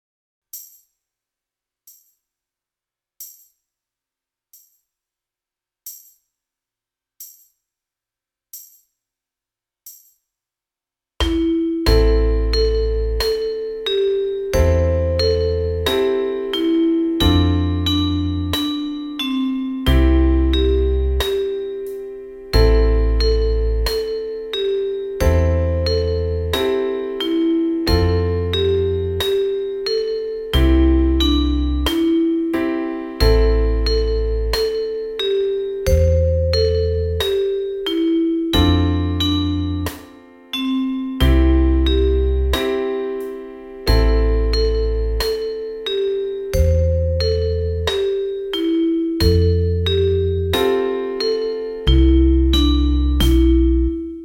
Soundbeispiel – Melodie & Band sowie Band alleine: